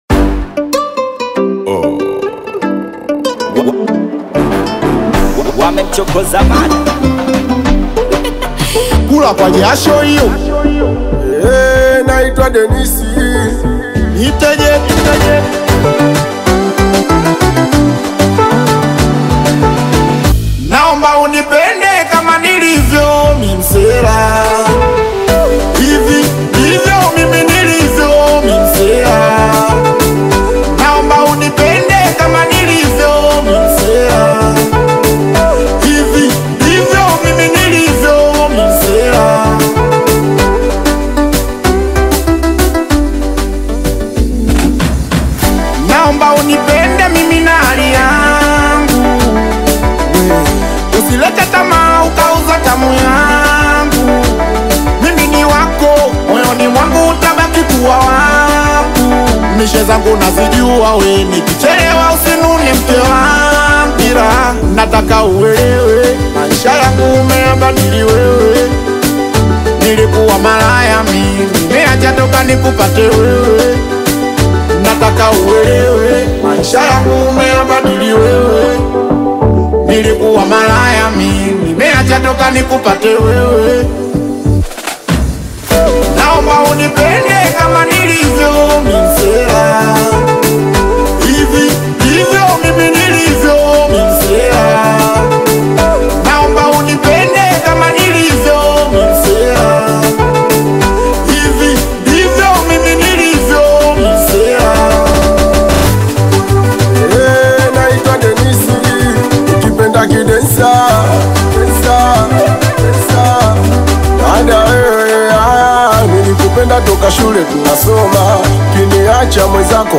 energetic Singeli rhythms